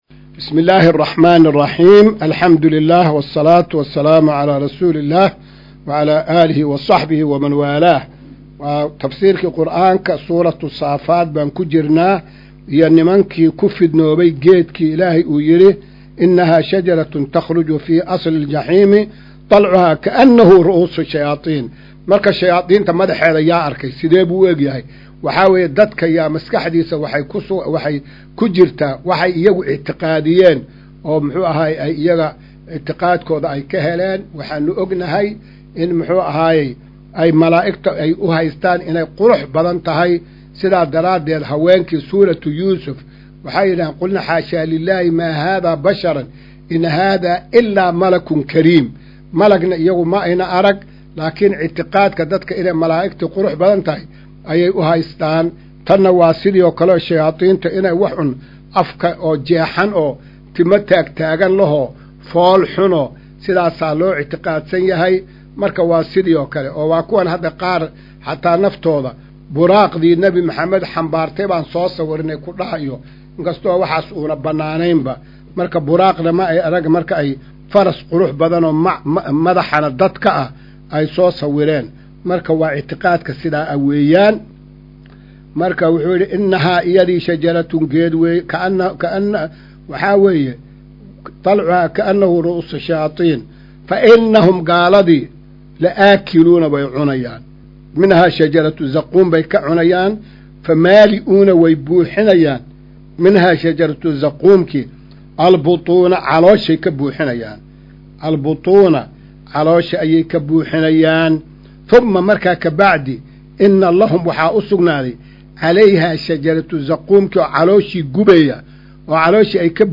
Maqal:- Casharka Tafsiirka Qur’aanka Idaacadda Himilo “Darsiga 213aad”